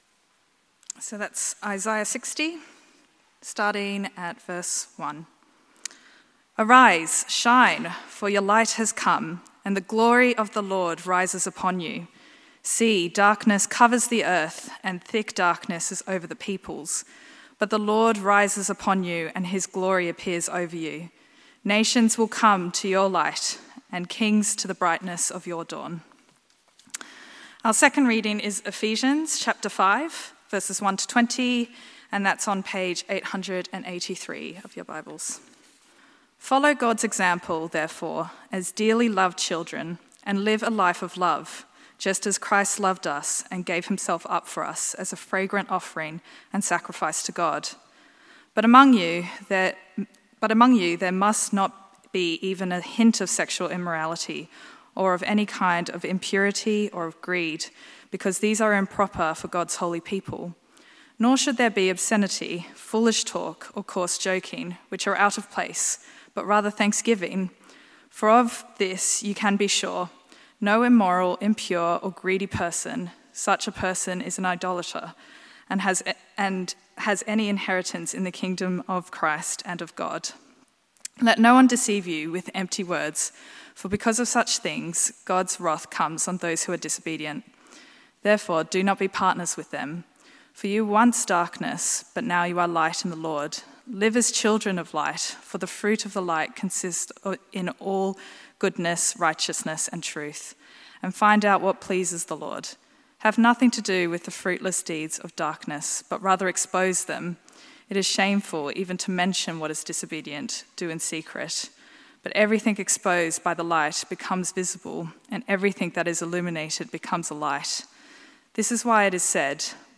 This is the eighth sermon in the sermon series Better Together looking at Paul’s letter to the Ephesians. This sermon focusses on Ephesians 5:1-20 and also looks at Isaiah 60:1-3.